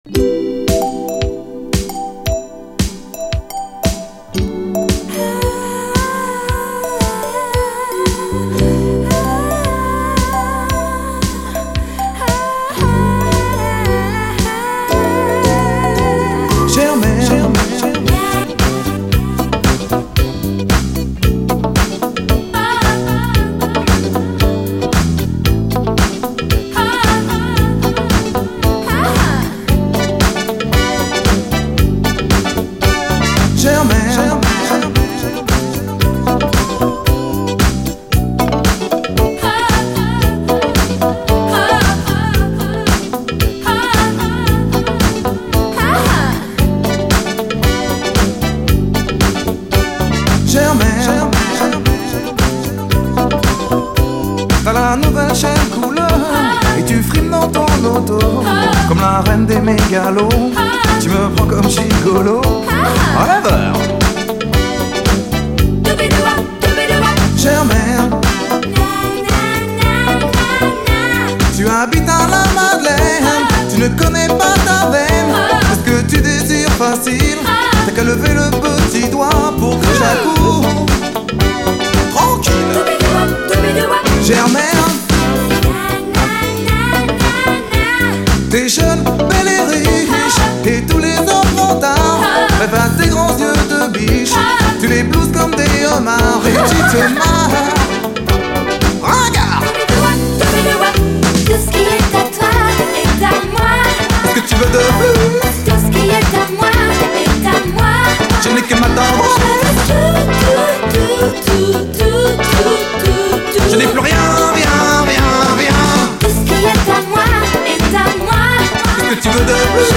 DISCO
キャッチー＆ファンシー＆トロピカルに圧倒するキラー・フレンチ・ブギー12インチ！
試聴ファイルはこの盤からの録音です/　フレンチ・ブギーを代表するキラー12インチ！
お洒落にエディットされたイントロがある、12インチ・オンリーのREMIXヴァージョン！